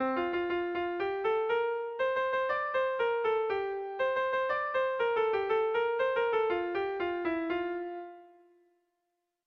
Kopla handia
ABD